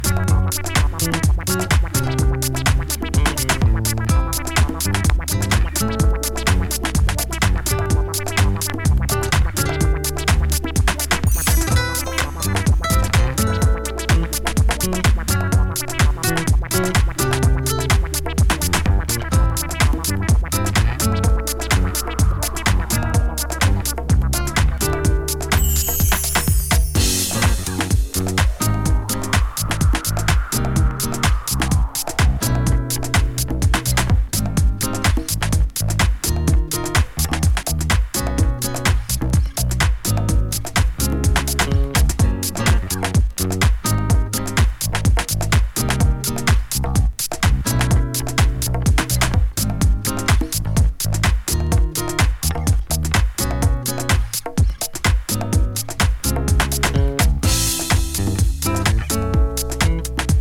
こちらはオーガニックなDeep House的サウンド。